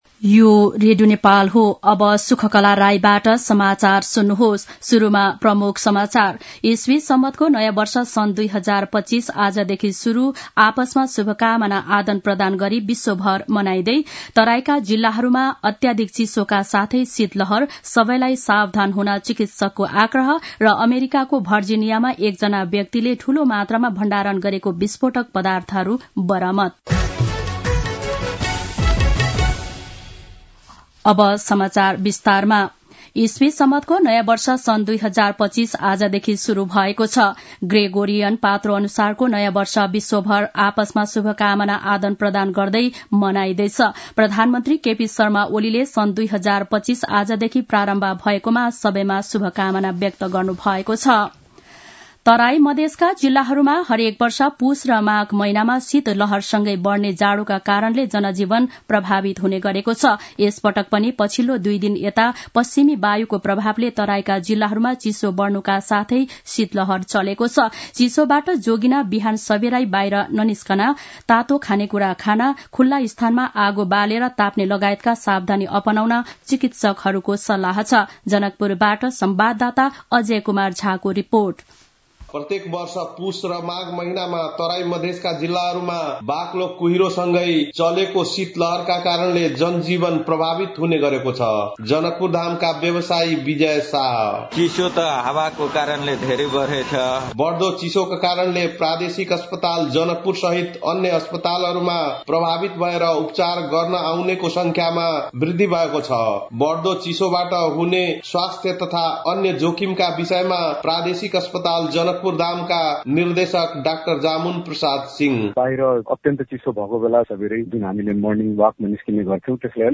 दिउँसो ३ बजेको नेपाली समाचार : १८ पुष , २०८१
3-pm-nepali-news-.mp3